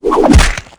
bash1.wav